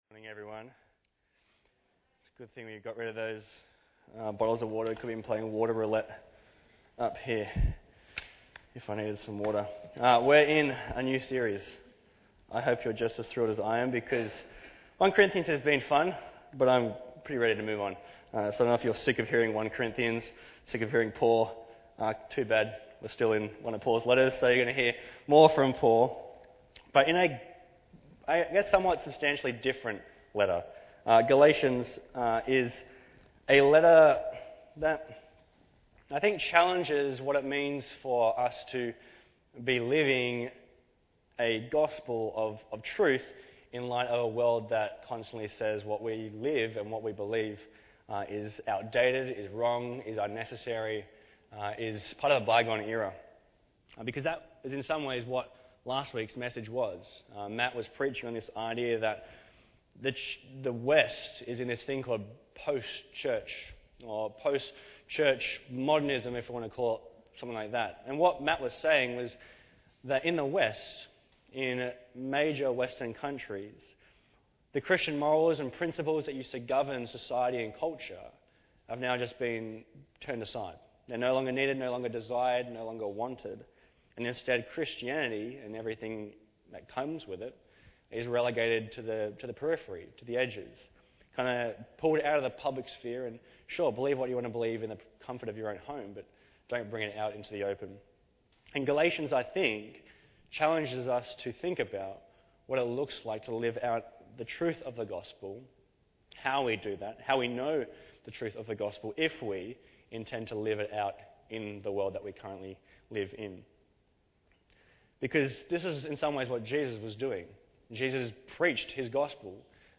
Bible Text: Galatians 1:1-10 | Preacher